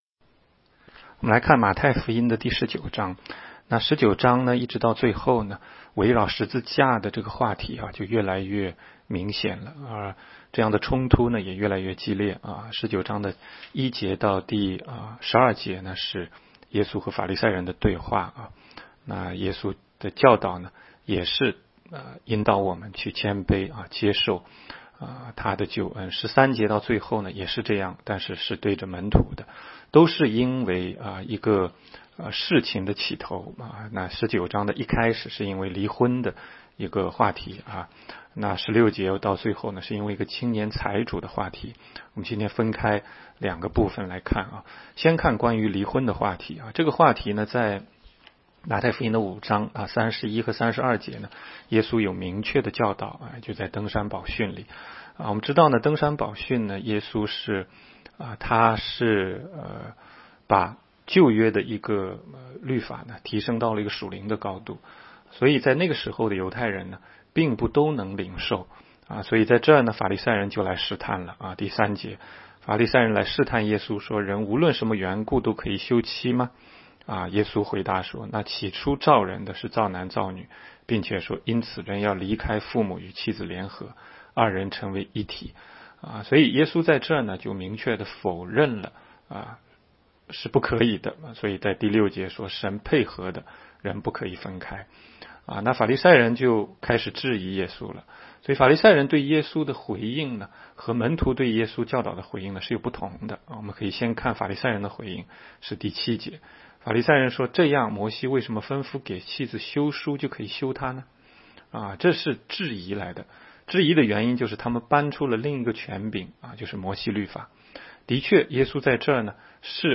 每日读经